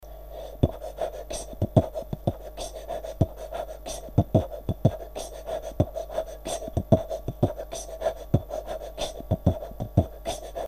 Зацените пару битов оч маленьких небольших)))) дополните если что)правда качесто из за микорфона плохое(
во втором ты говоришь "киска" вместо снэра?)
Помоему во 2 записи кэс нормально звучит а вот в 1 хендклэп плохой и нечего смешного тут нету!!!!!
Херово звучит и то и то)